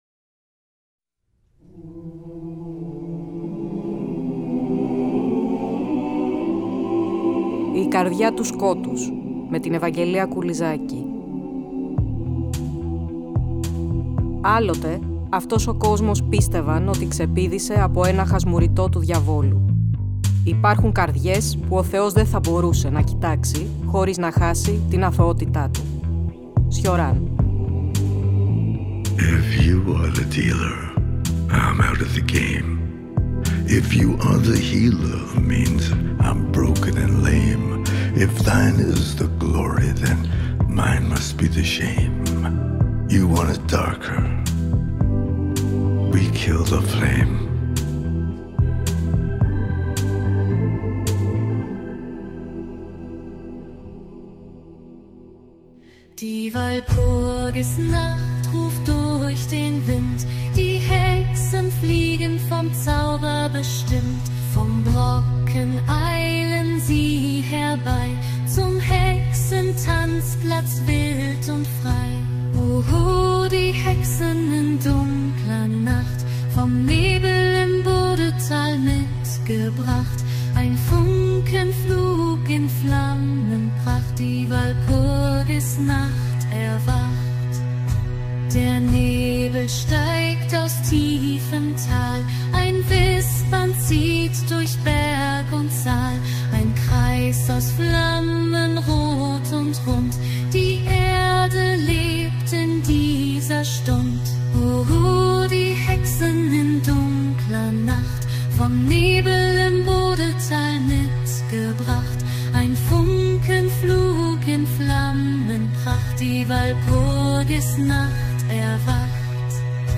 Ακούστε το 15ο επεισόδιο του τρέχοντος Κύκλου της εκπομπής, που μεταδόθηκε την Κυριακή 14 Δεκεμβρίου από το Τρίτο Πρόγραμμα.